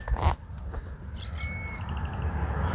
Federal (Amblyramphus holosericeus)
Nombre en inglés: Scarlet-headed Blackbird
Provincia / Departamento: Entre Ríos
Condición: Silvestre
Certeza: Observada, Vocalización Grabada